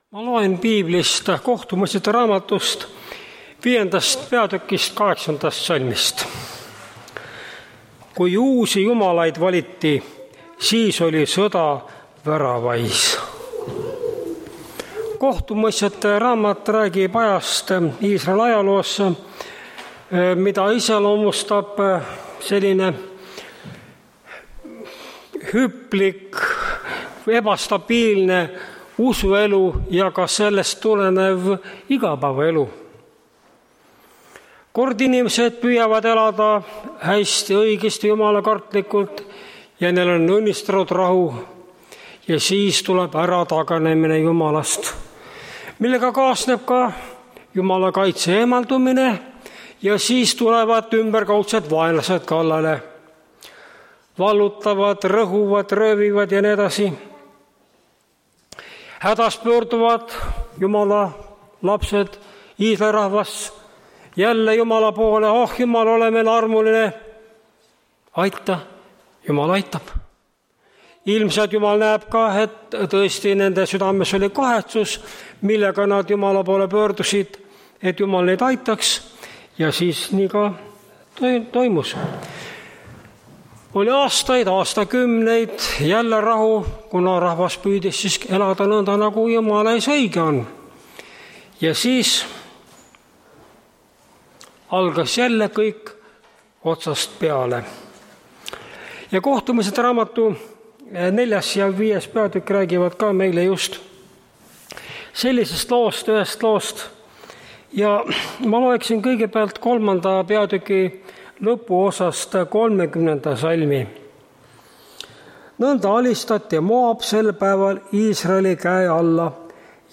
Tartu adventkoguduse 02.07.2022 hommikuse teenistuse jutluse helisalvestis.